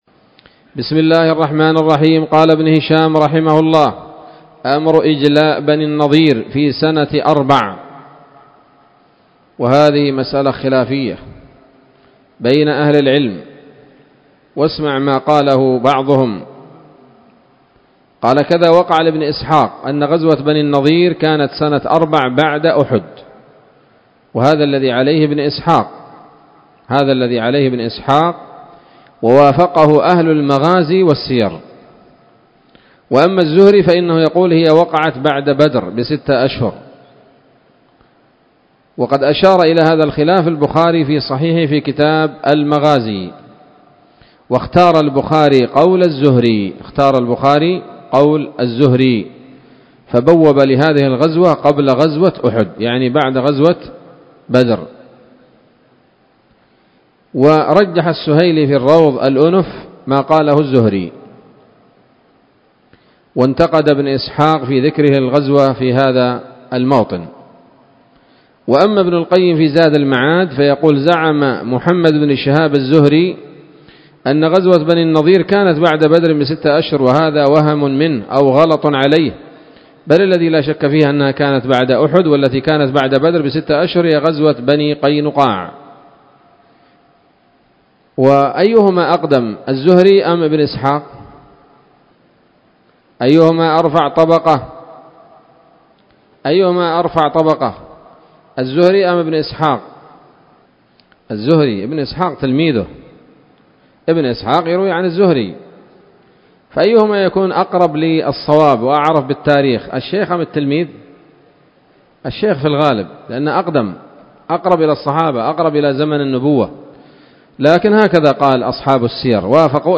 الدرس الثامن والثمانون بعد المائة من التعليق على كتاب السيرة النبوية لابن هشام